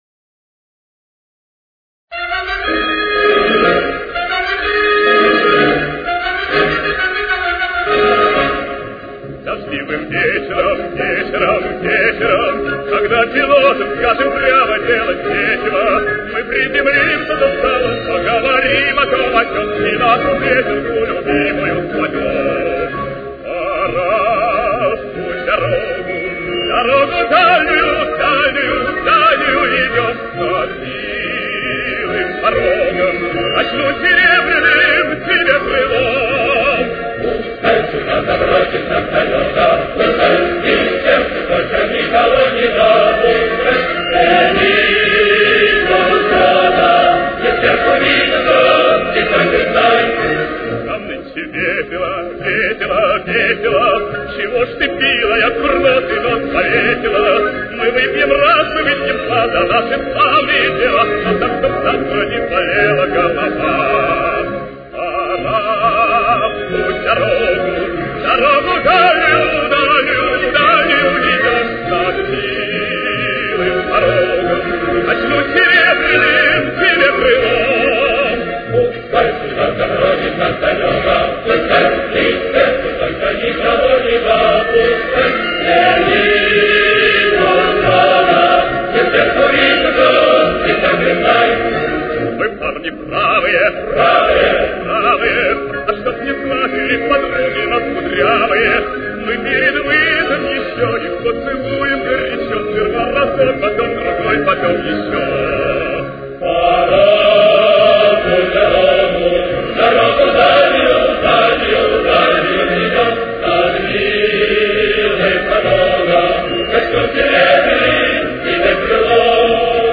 с очень низким качеством (16 – 32 кБит/с)
Ре-бемоль мажор. Темп: 142.